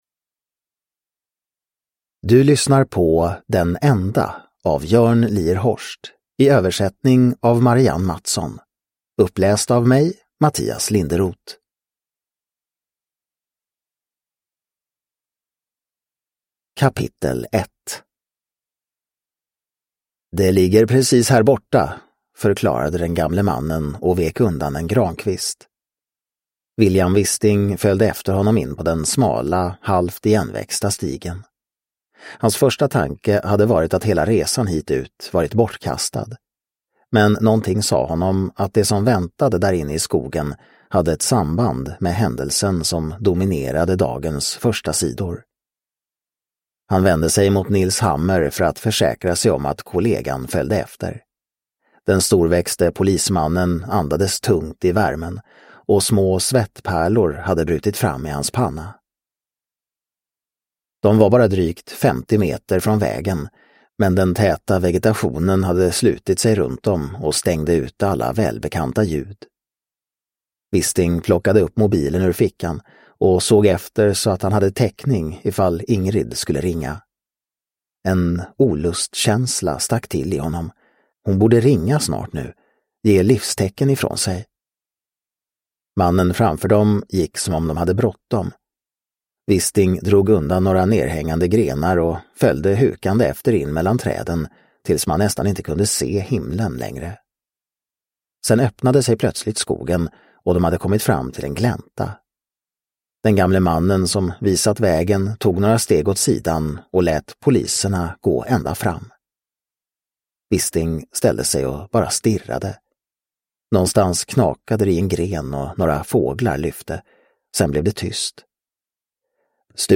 Den enda – Ljudbok – Laddas ner